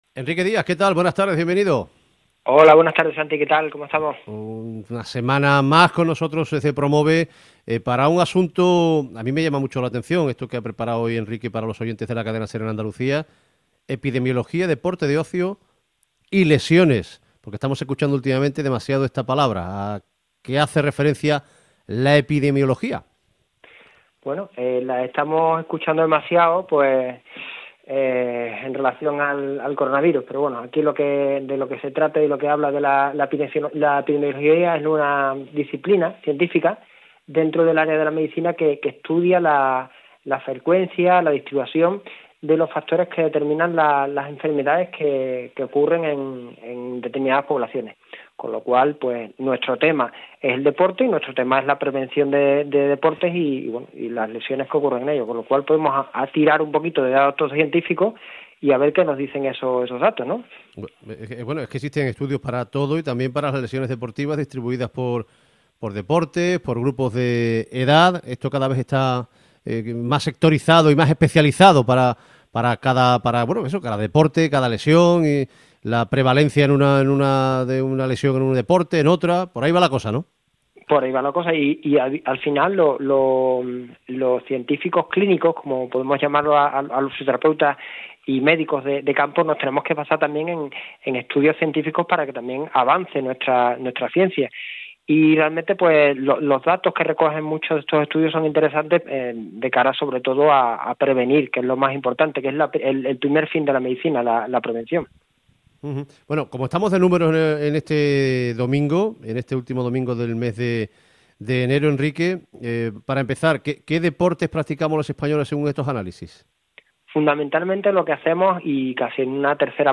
entrevista
Emitido el domingo 30 de enero de 2022, a través de la web de Cadena SER.